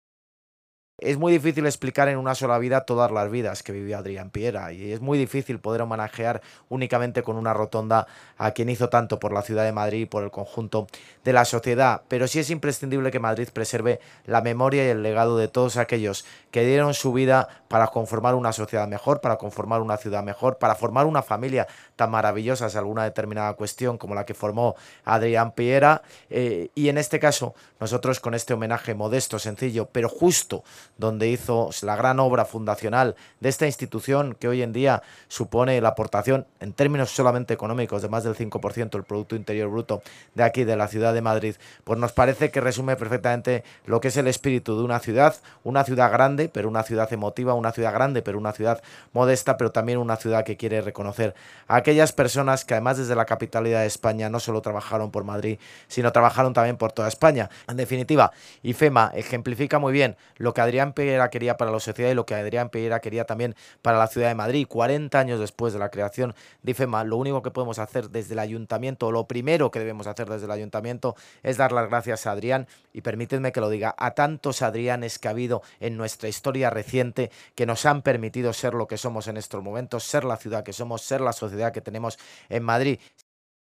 Nueva ventana:Audio de la intervención del alcalde de Madrid, José Luis Martínez-Almeida, en el acto de descubrimiento de la placa de la glorieta Adrián Piera Jiménez